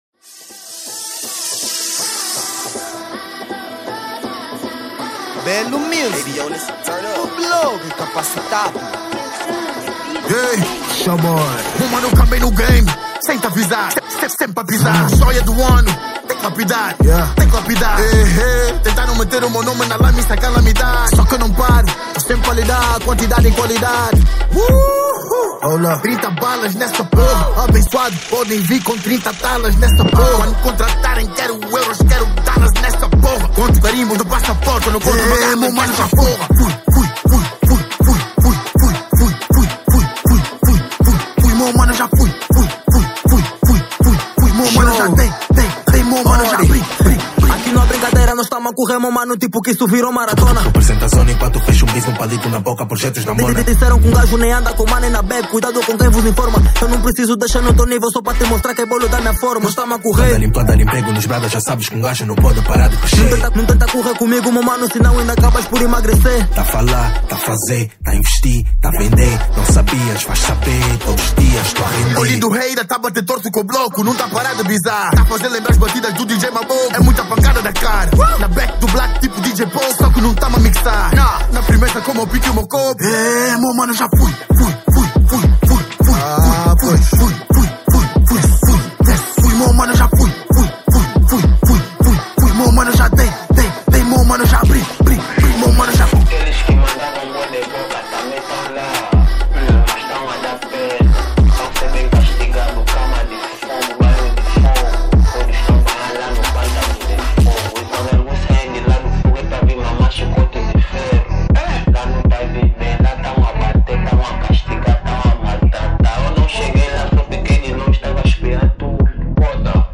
Gênero : Rap